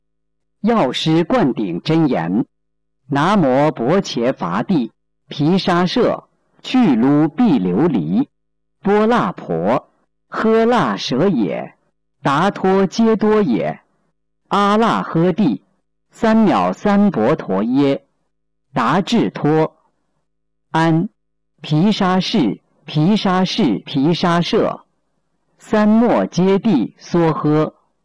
013《药师灌顶真言》教念男声